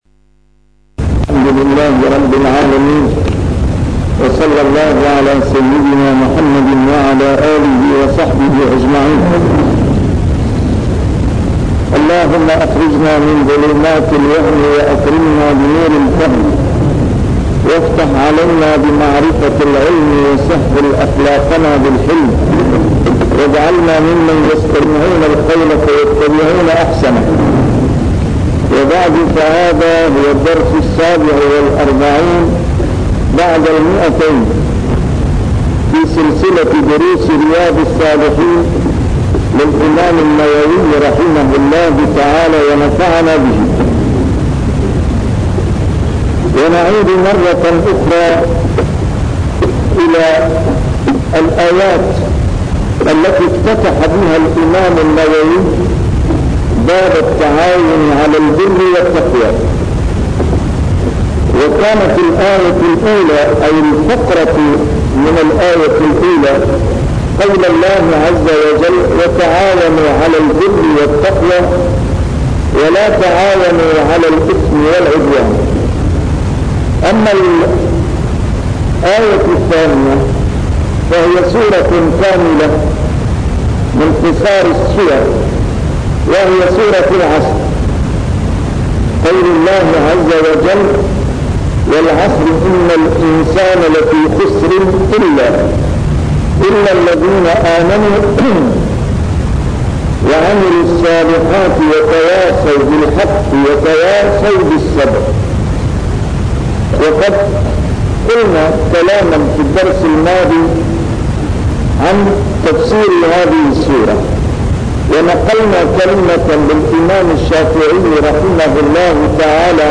A MARTYR SCHOLAR: IMAM MUHAMMAD SAEED RAMADAN AL-BOUTI - الدروس العلمية - شرح كتاب رياض الصالحين - 247- شرح رياض الصالحين: التّعاون على البرّ والتّقوى